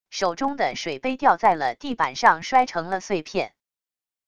手中的水杯掉在了地板上摔成了碎片wav音频